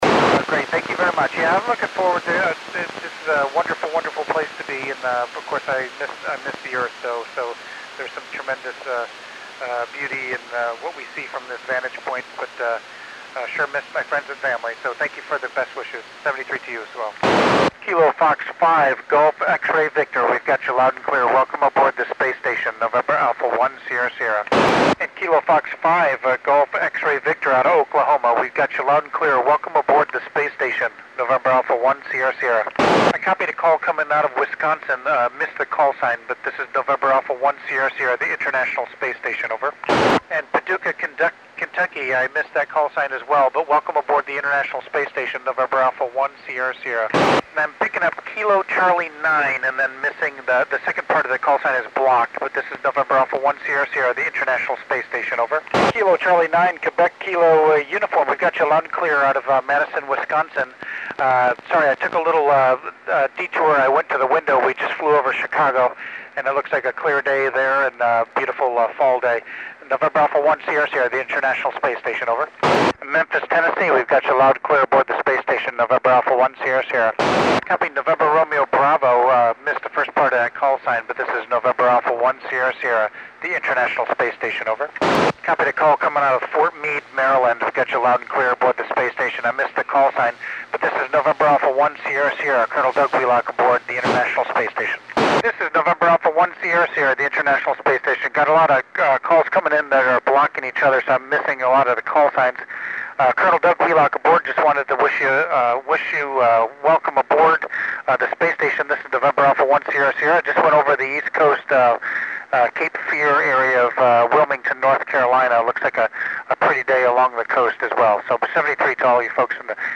Col. Doug Wheelock (NA1SS) works U.S. and Cuban stations on 09 October 2010 at 1409 UTC.
Col. Doug Wheelock (NA1SS) wks U.S. and Cuban stations